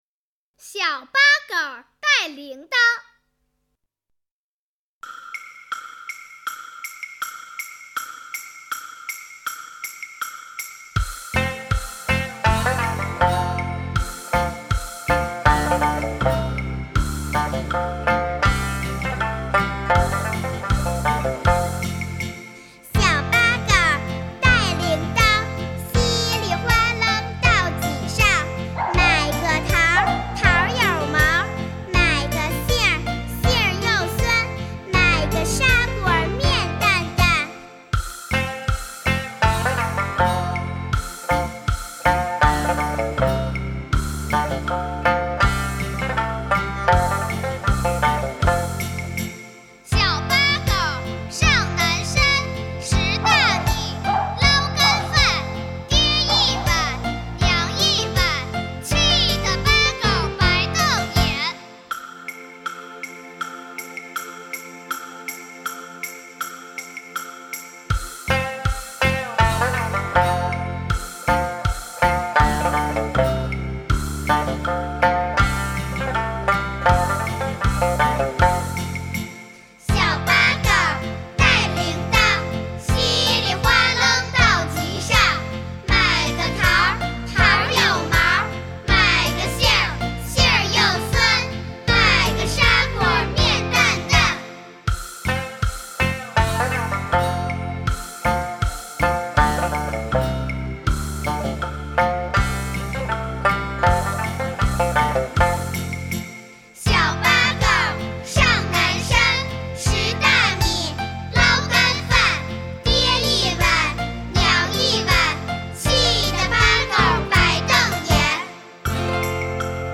专辑风格：中国童谣
北京地区为主、覆盖全国范围的最为经典的传统民间童谣和儿歌。